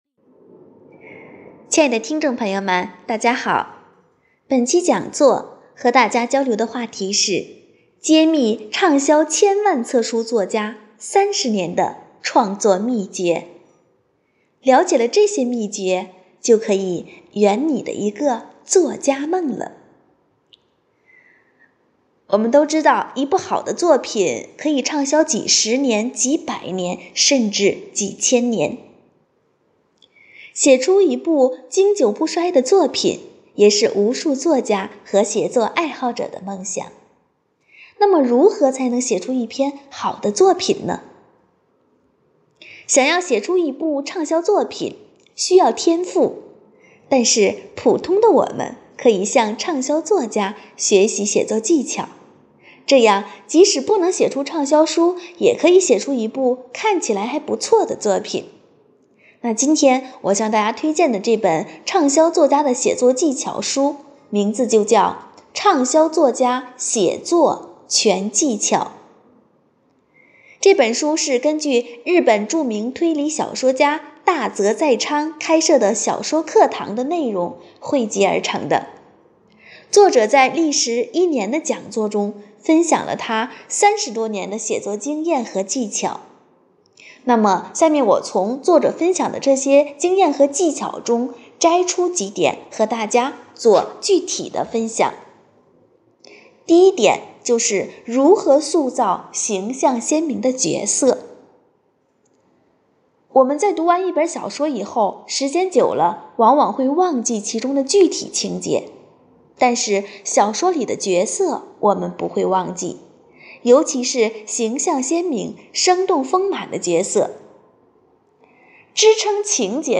活动预约 - 【讲座】圆你作家梦——揭秘畅销千万册书作家30年的创作秘诀